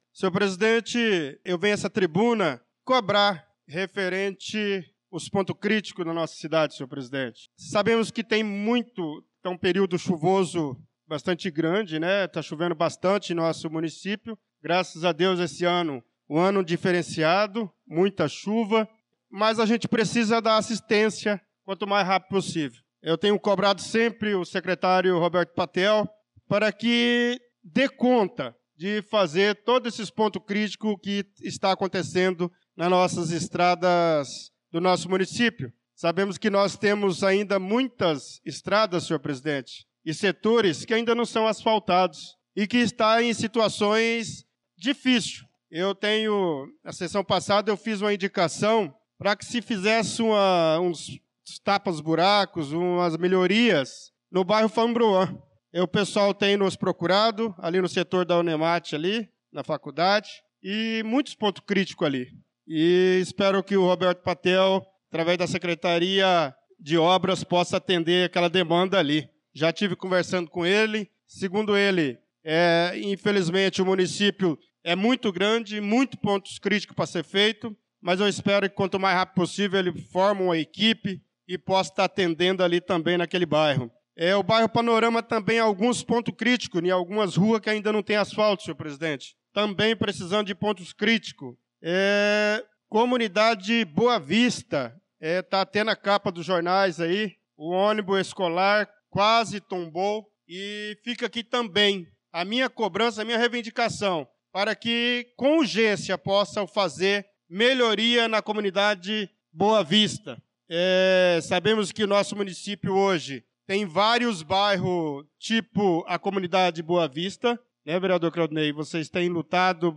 Pronunciamento do vereador Bernardo Patrício na Sessão Ordinária do dia 11/03/2025